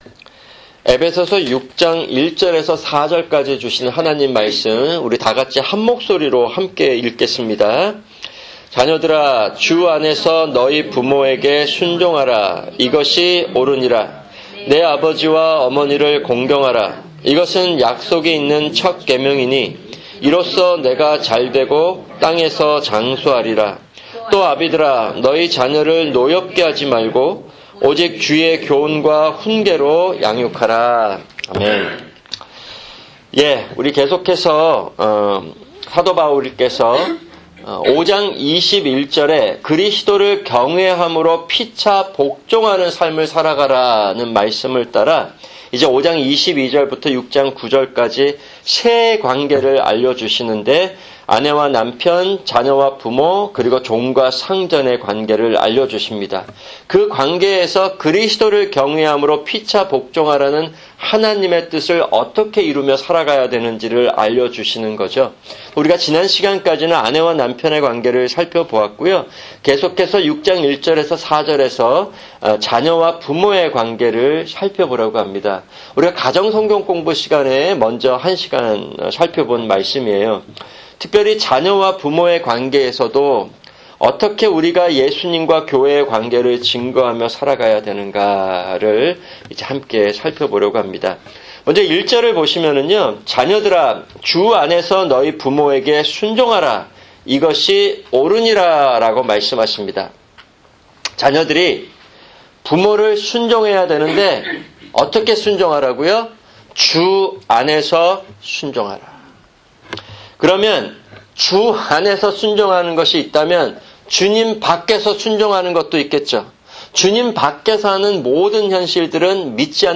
[금요 성경공부] 에베소서6:1-4(2)